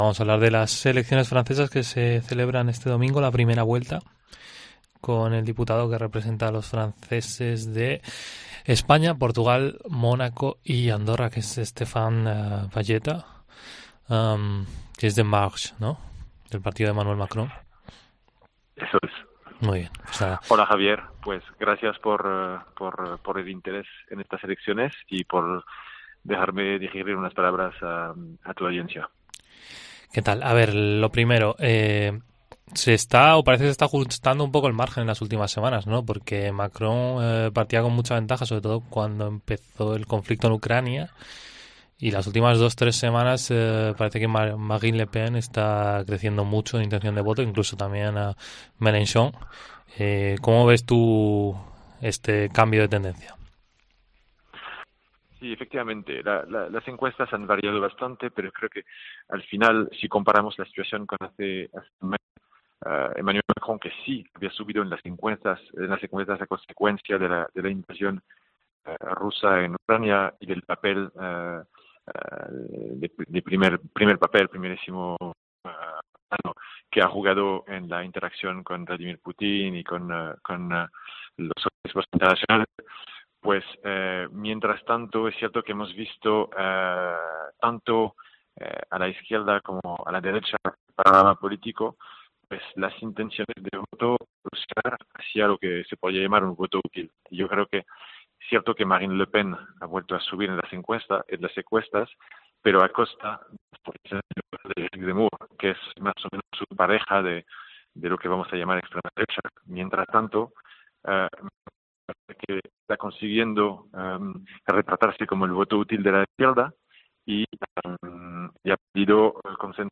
El diputado que representa a los franceses que viven en España, Stéphane Vojetta, analiza la primera vuelta de las elecciones francesas